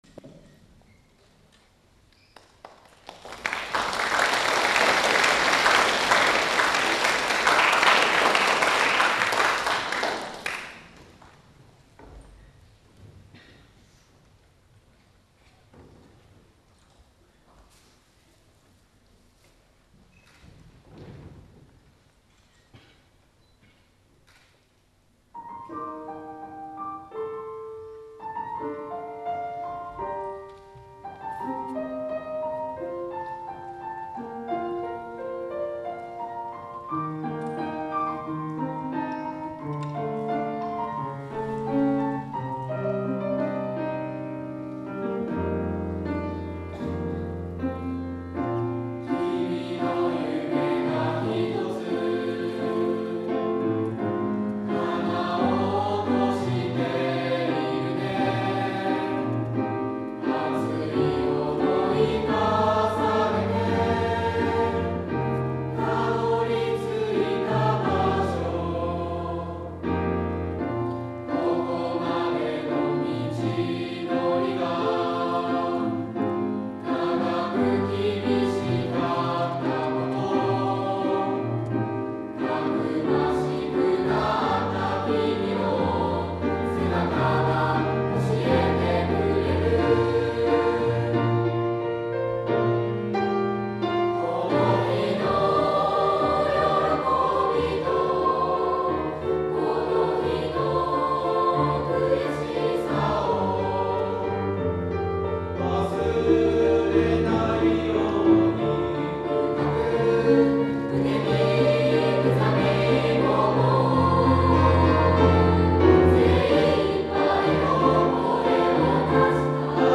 ～きれいなハーモニーが響きわたりました～
11月8日（金），中学・高校の合唱コンクールが行われました。
中学校の部で見事第1位に輝いた3年1組「時を越えて」を聴くことができます。